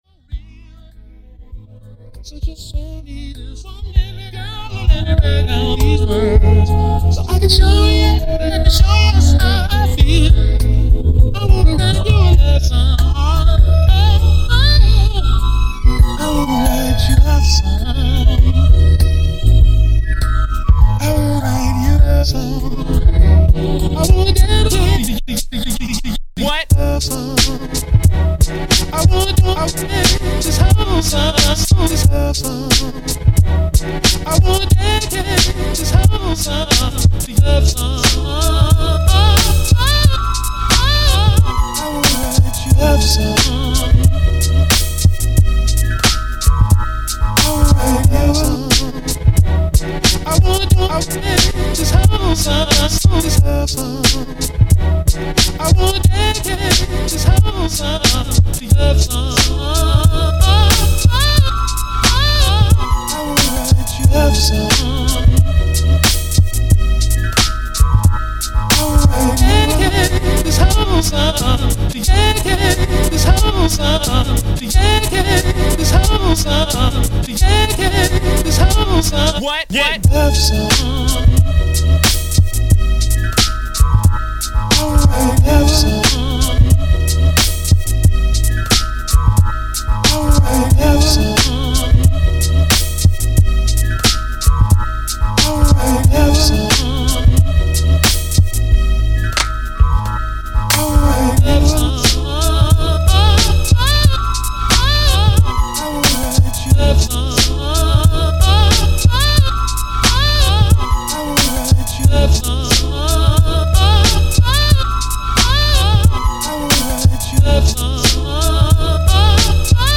beat tape